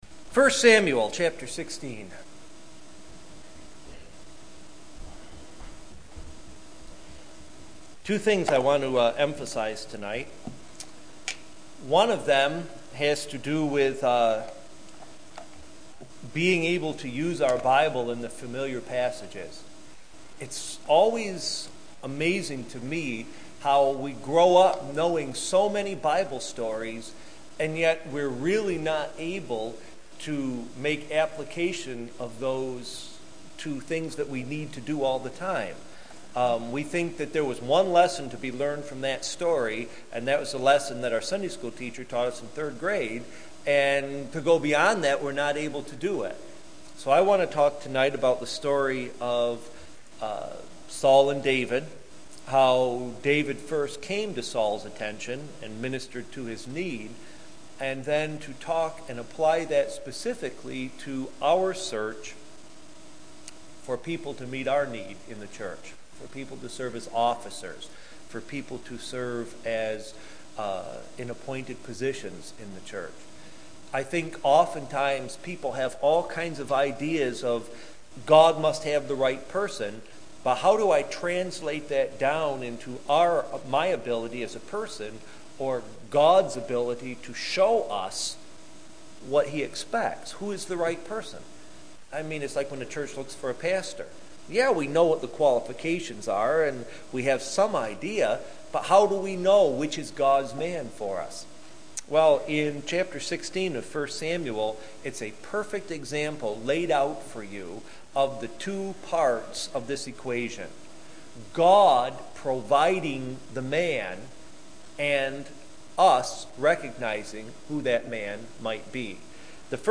Unclassified Passage: 1 Samuel 16:1 Church: Brooktondale PM %todo_render% « The Basic Doctrine of Regeneration